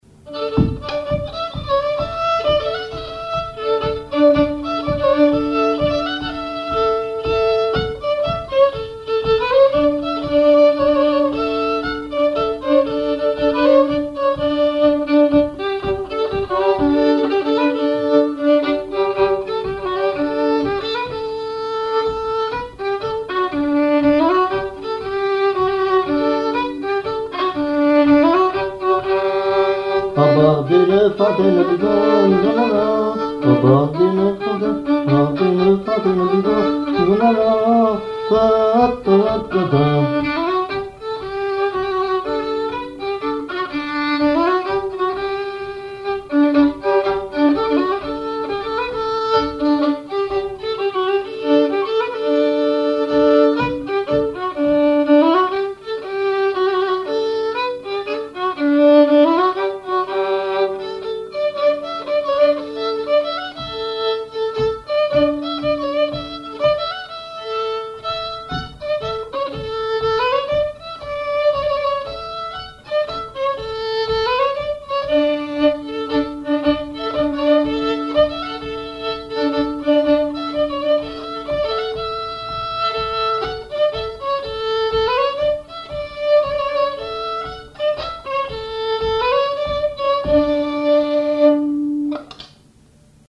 Mémoires et Patrimoines vivants - RaddO est une base de données d'archives iconographiques et sonores.
violoneux, violon
gestuel : à marcher
instrumentaux au violon mélange de traditionnel et de variété
Pièce musicale inédite